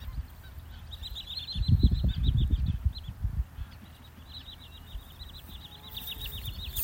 Birds -> Waders ->
Wood Sandpiper, Tringa glareola